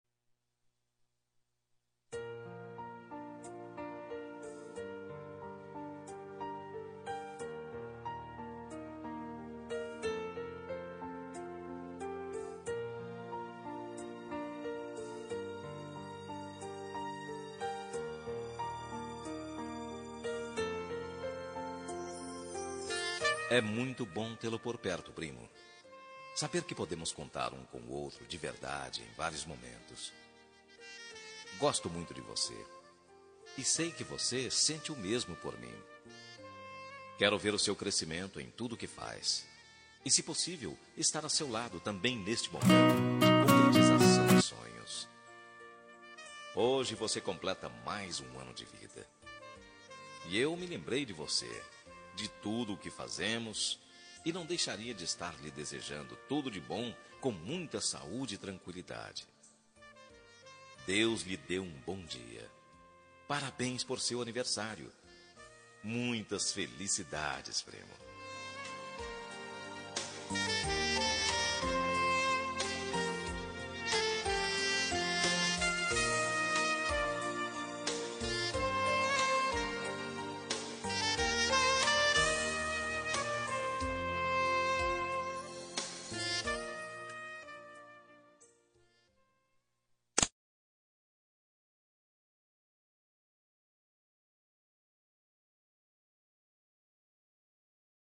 Aniversário de Primo – Voz Masculina – Cód: 042834